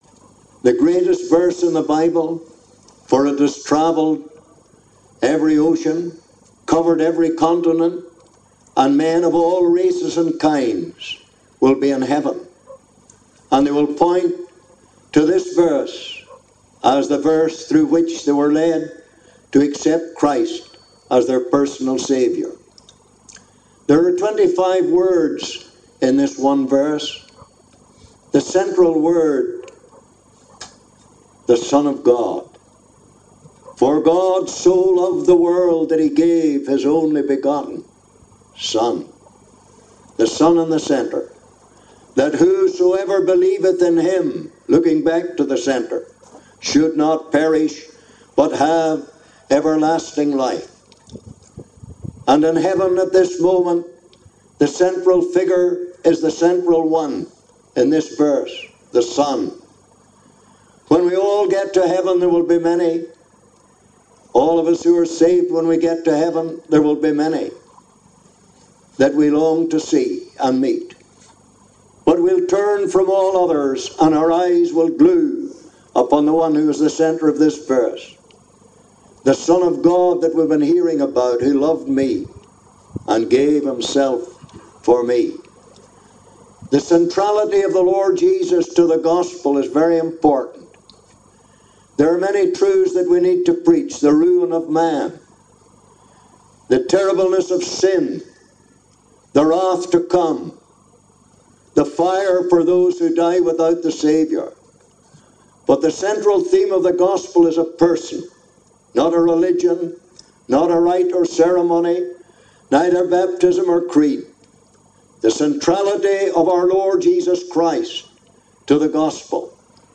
(Recorded in Portstewart, Northern Ireland)
Historical Gospel Sermons